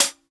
TIGHT_HH.wav